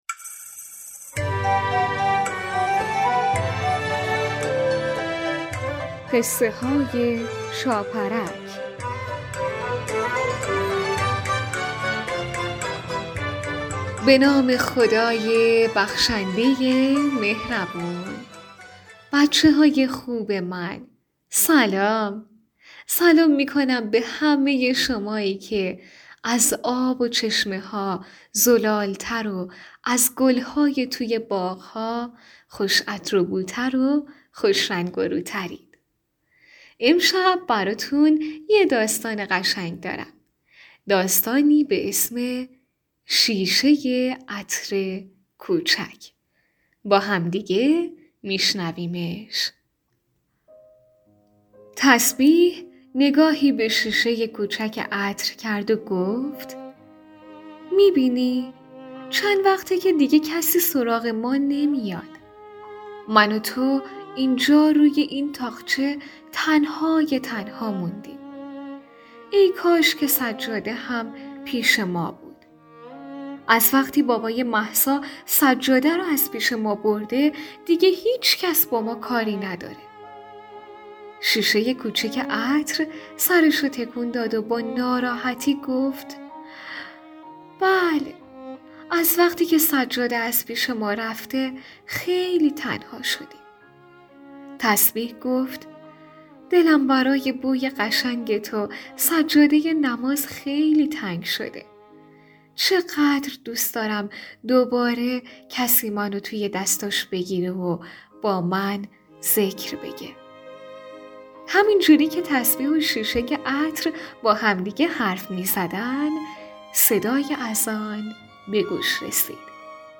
این ویژه برنامه با هدف نهادینه شدن فرهنگ نماز در بین کودکان با بیان قصه های شیرین نمازی تولید و منتشر می شود.